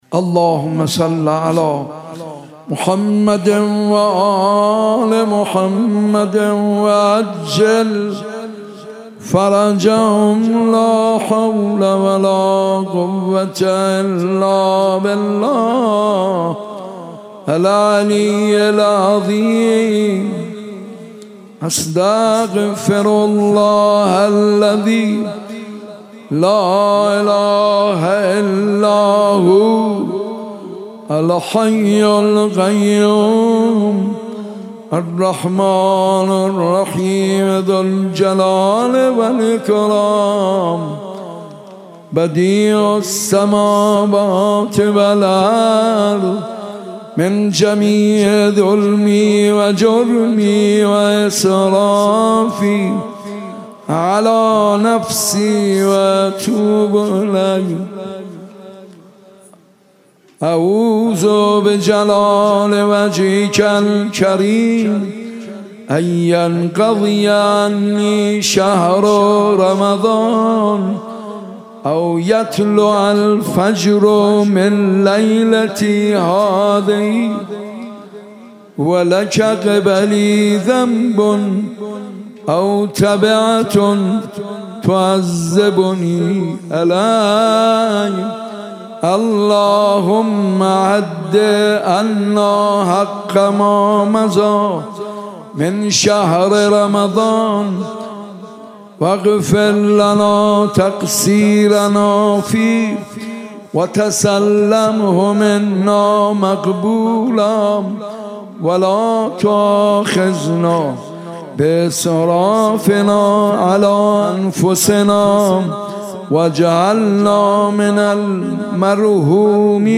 مناجات خوانی حاج منصور ارضی در شب بیست و هشتم ماه مبارک رمضان + متن
دانلود مناجات زیبا و دلنشین از حاج منصور ارضی در شب بیست و هشتم ماه رمضان به همراه متن